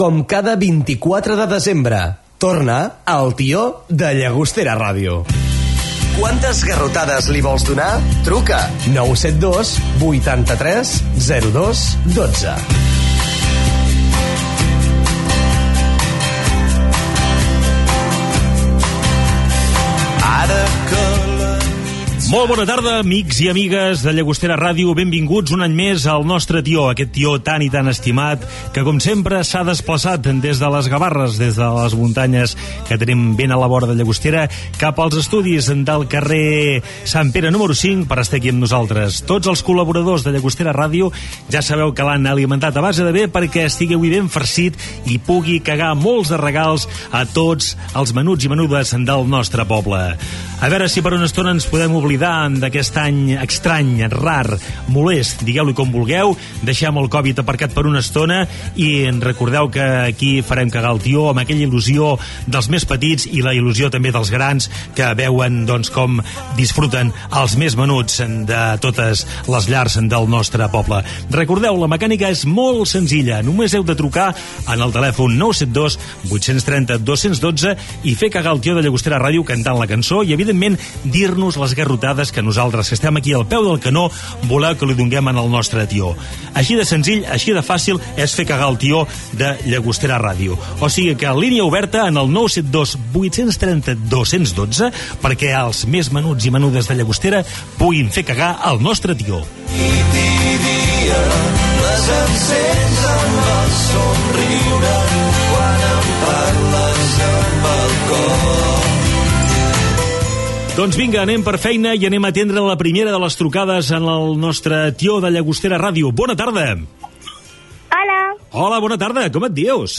Inici del programa: salutació, com participar-hi i primera trucada.
Entreteniment